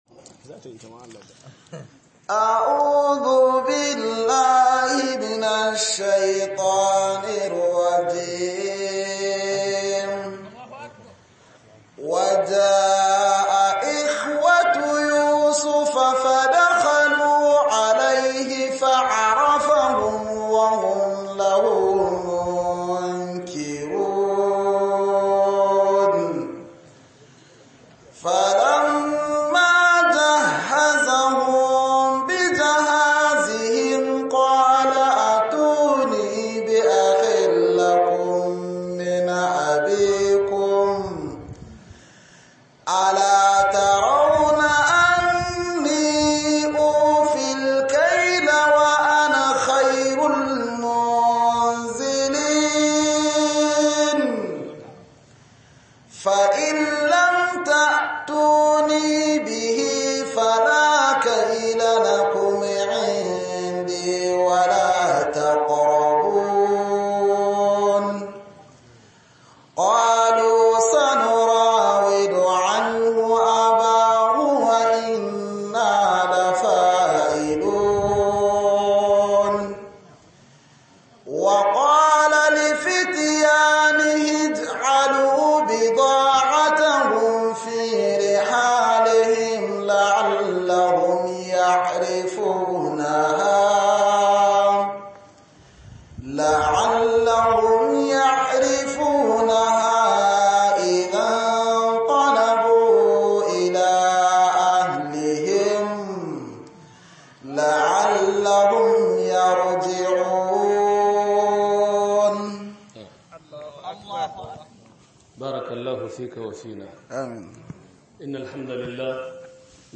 كراهية التسخط البنات~1 - MUHADARA